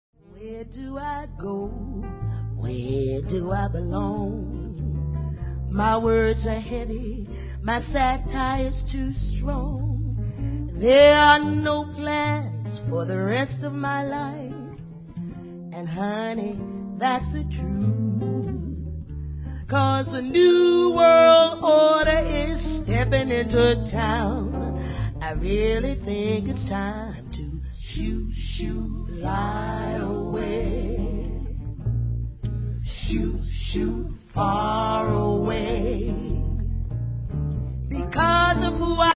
Violin
Sax, vocals
Grand Piano
Guitars
Live recording Nieuwe de la Mar theater Amsterdam